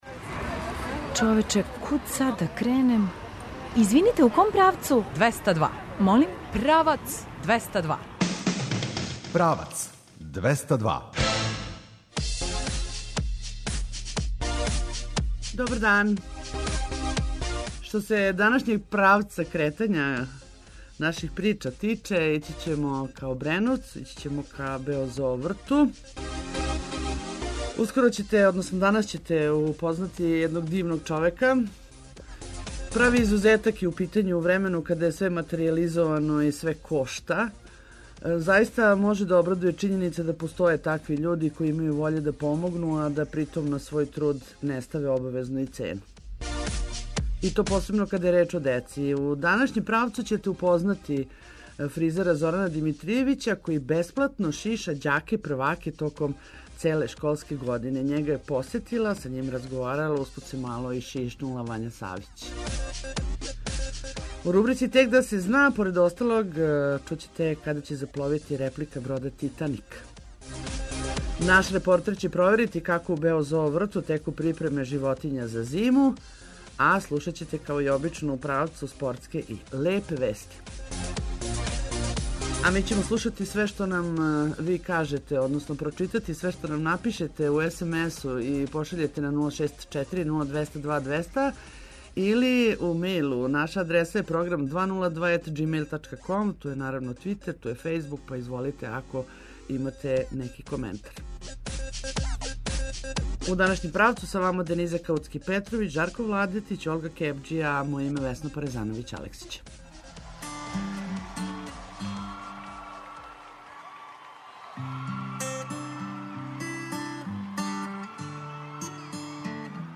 Као и увек, слушаћете спортске и лепе вести а наш репортер сазнаће како се у Бео зоо врту животиње припремају за зиму.